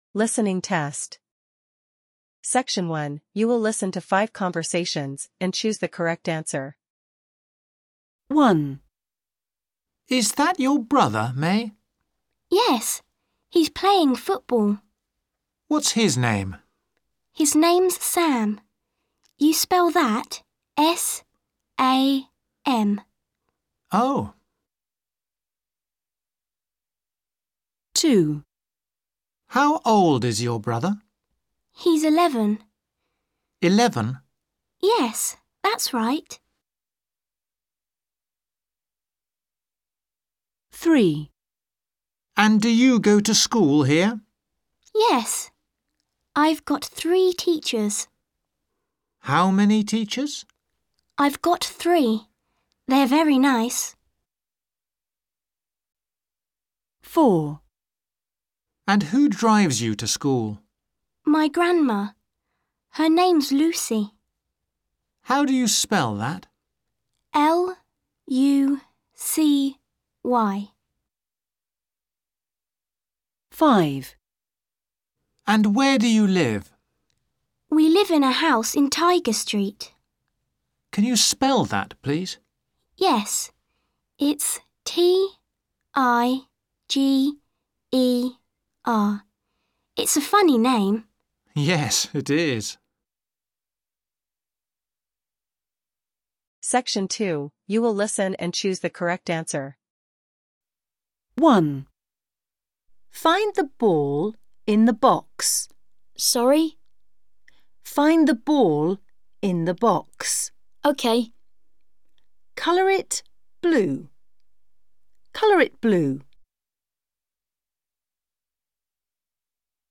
SECTION 1: (10pts - 2pts each)You will listen to 5 conversations and choose the correct answer.
Listening-test-Grade-123.mp3